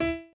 admin-fishpot/b_pianochord_v100l12o5e.ogg at main